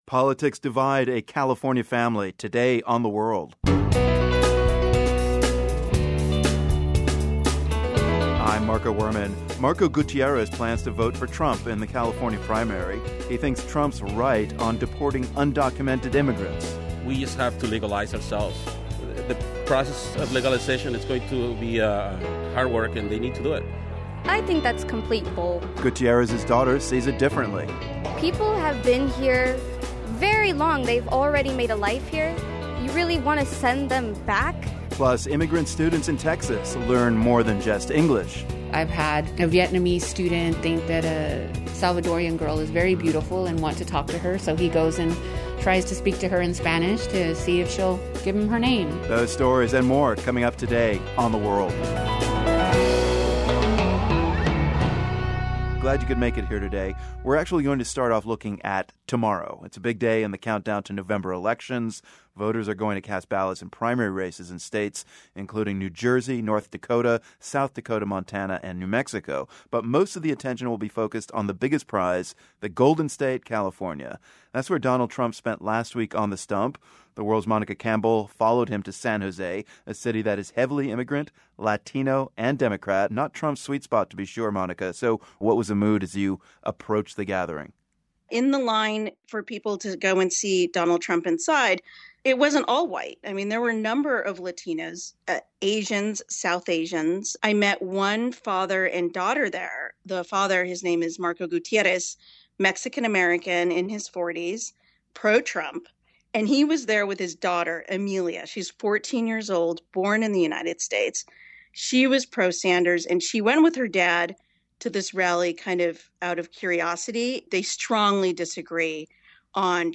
We have an extended conversation with trumpeter and band-leader Herb Alpert.